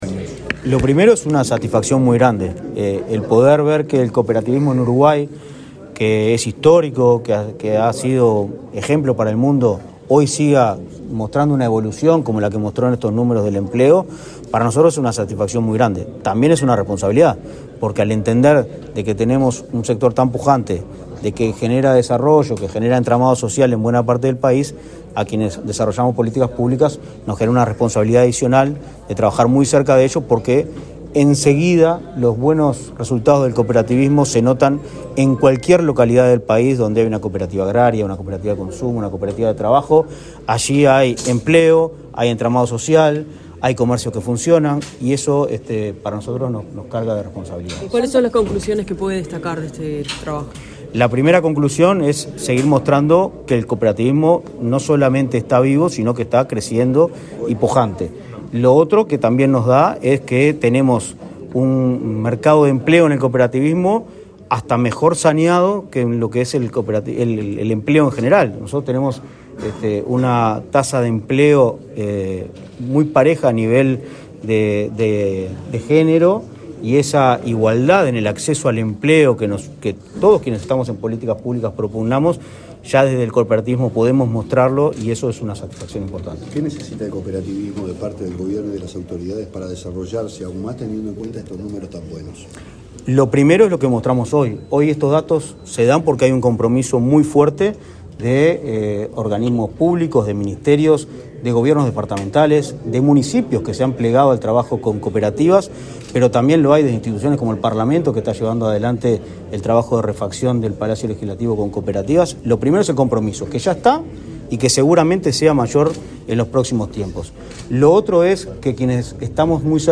Declaraciones del presidente de Inaccop, Martín Fernández
Declaraciones del presidente de Inaccop, Martín Fernández 27/04/2023 Compartir Facebook Twitter Copiar enlace WhatsApp LinkedIn El presidente de Instituto Nacional del Cooperativismo (Inacoop), Martín Fernández, participó este jueves 27 en Montevideo en la presentación de un nuevo informe de empleo, que muestra la evolución y cifras relativas a este tema en nuestro país, en especial, en el sector cooperativo. Luego dialogó con la prensa.